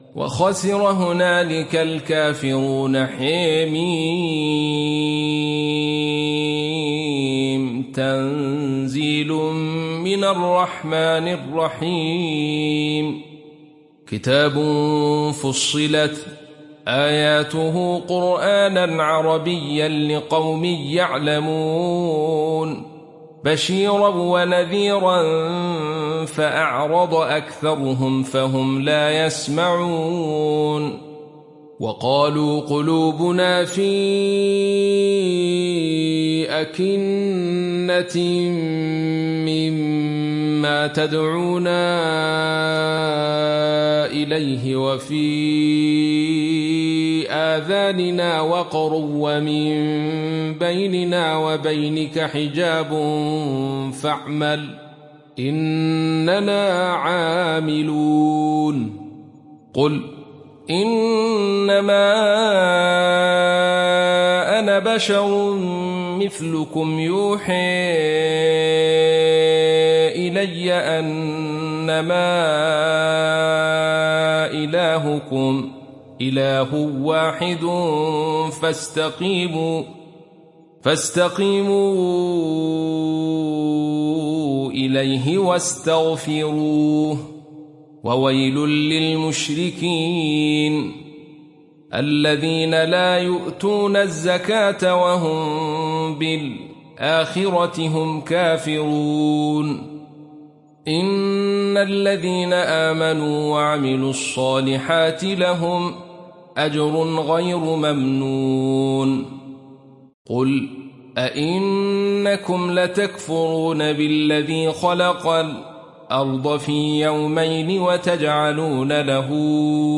Surat Fussilat Download mp3 Abdul Rashid Sufi Riwayat Khalaf dari Hamza, Download Quran dan mendengarkan mp3 tautan langsung penuh